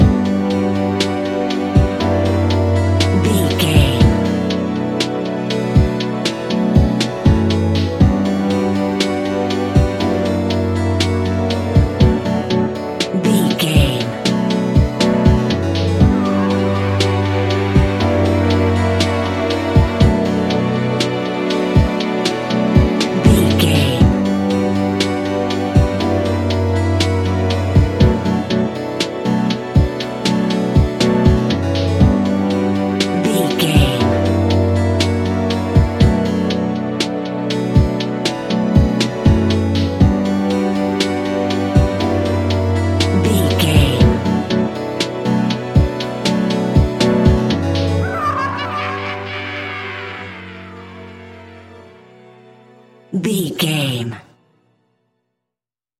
Ionian/Major
B♭
chilled
laid back
Lounge
sparse
new age
chilled electronica
ambient
atmospheric